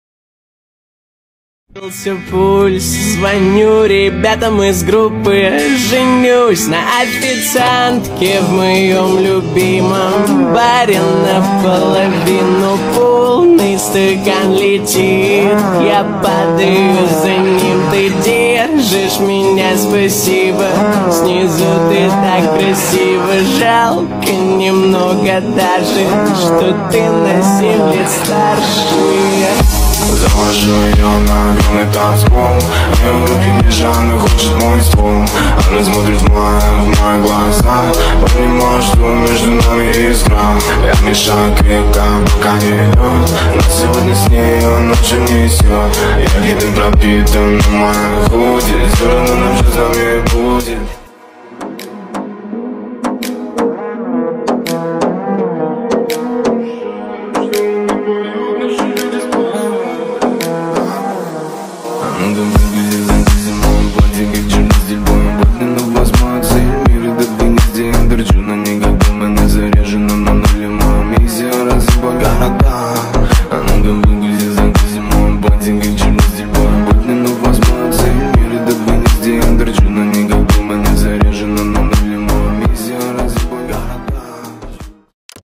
mashup remix
тик ток ремикс